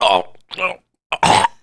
SCI-LASTBREATH1.WAV